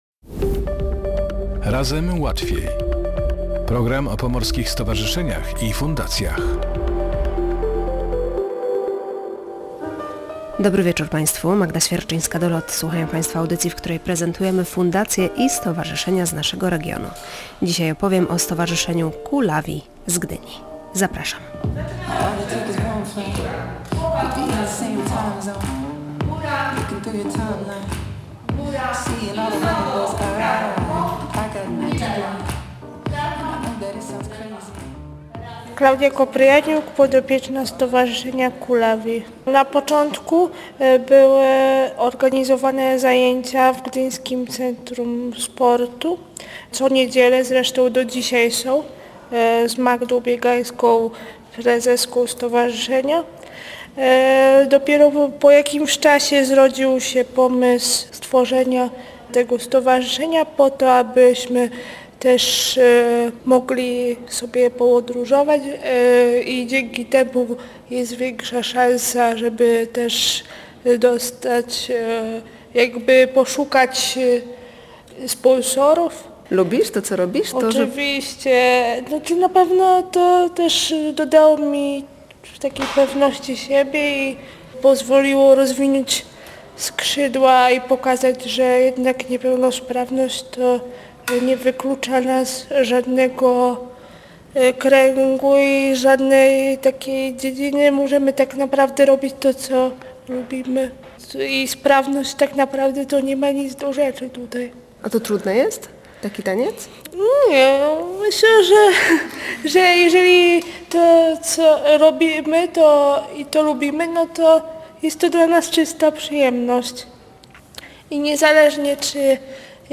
Grupa Dancing Wheels – tancerze na wózkach, którą założyło Stowarzyszenie COOLawi z Gdyni, była gościem audycji Razem Łatwiej.